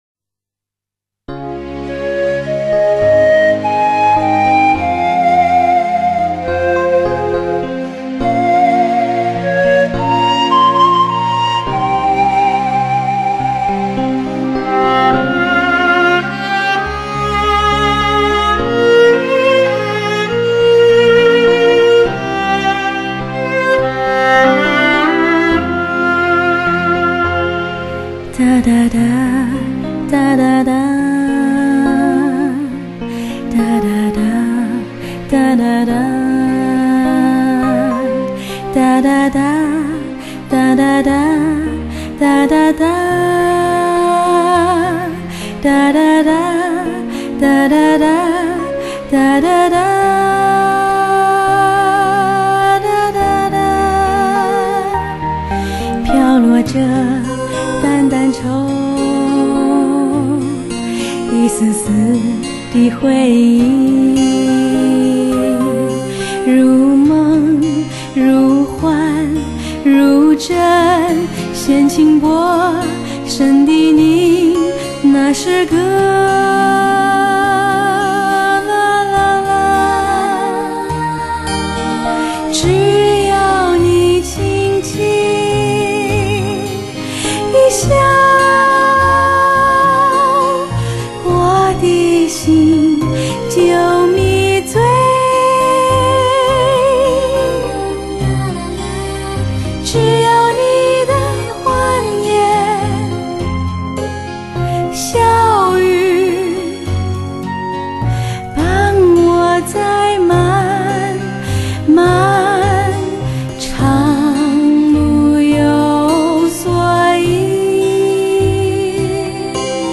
高亢的嗓音，投入的表演，让你得到最完美的身心享受。